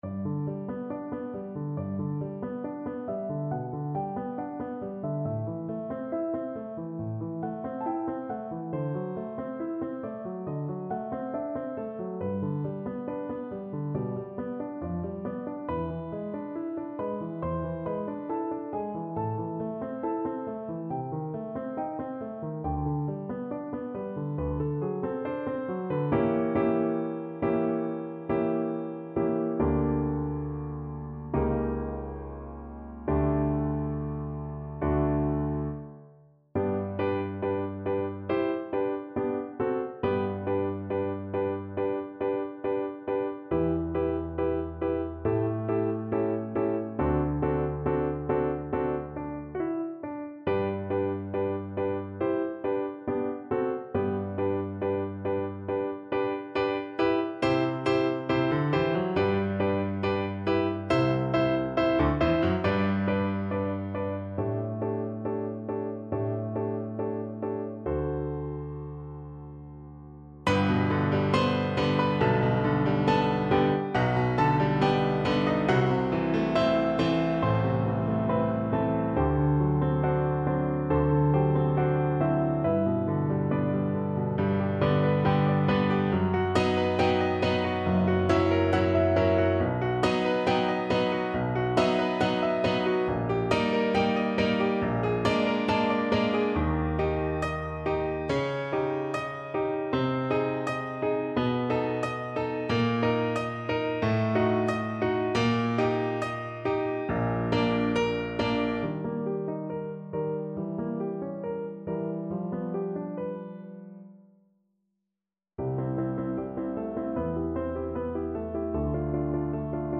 4/4 (View more 4/4 Music)
= 69 Andante